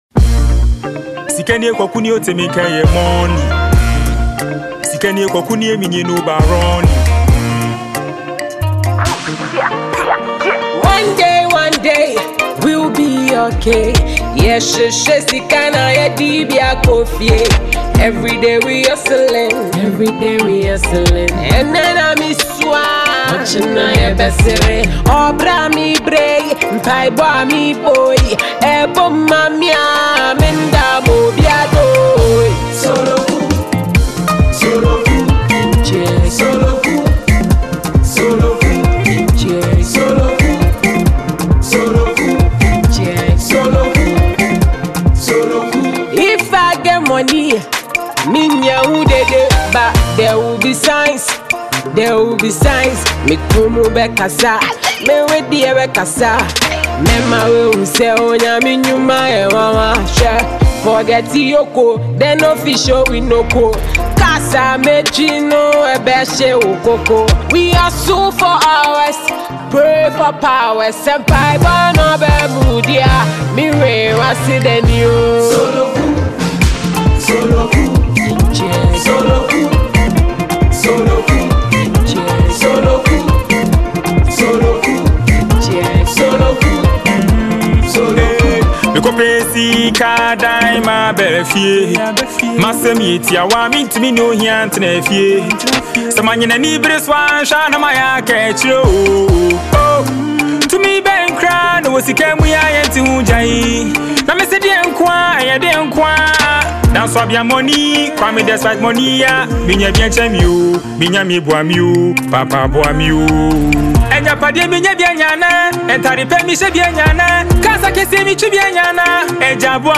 Genre: Highlife
Ghanaian female rapper